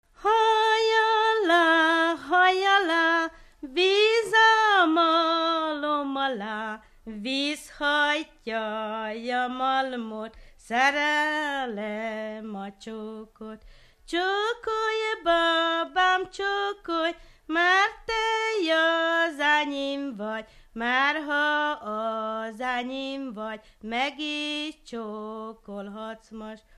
Dunántúl - Somogy vm. - Hódoshátihegy (Nikla)
ének
Stílus: 1.1. Ereszkedő kvintváltó pentaton dallamok